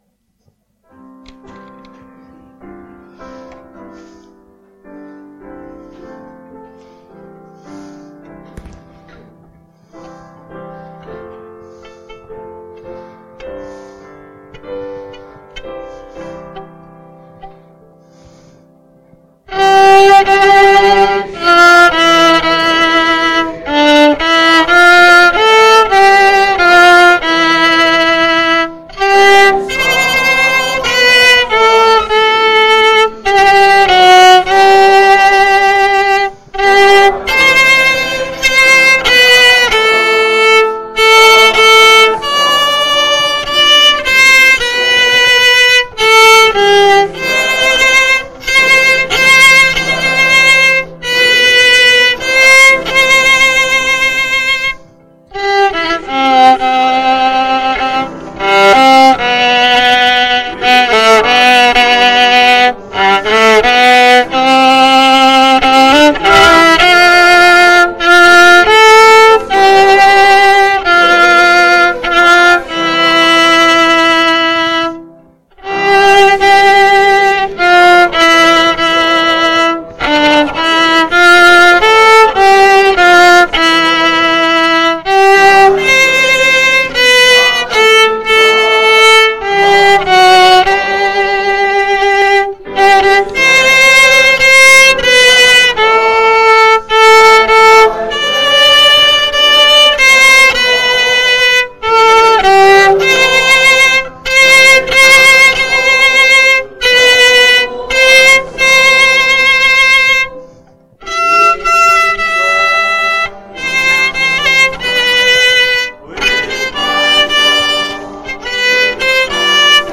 Special performances